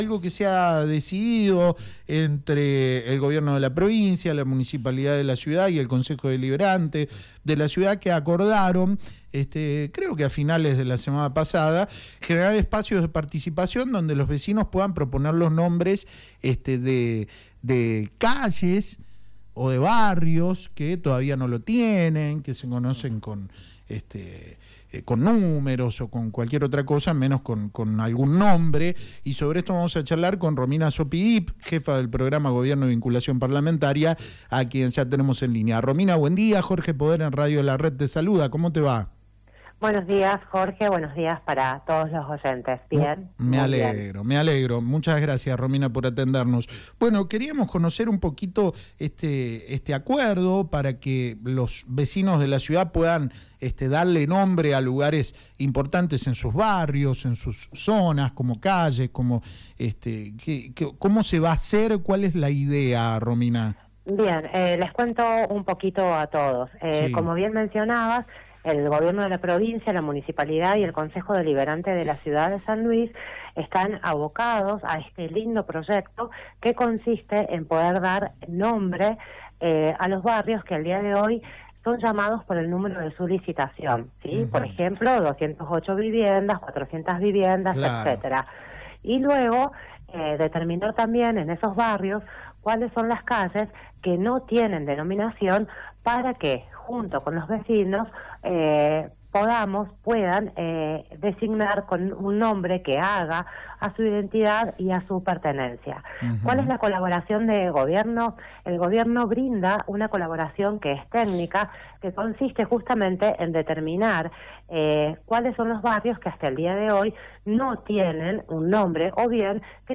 “La idea es empezar a ejecutar un programa que implica la acción conjunta del gobierno provincial, el Municipio y el Concejo Deliberante y que consiste en generar un espacio de participación con los vecinos y vecinas de la ciudad de San Luis para que puedan ponerle nombres a los barrios y calles que no lo tienen”, explicó Romina Zoppi Dip, jefa del Programa Gobierno y Vinculación Parlamentaria, en diálogo con La Red San Luis.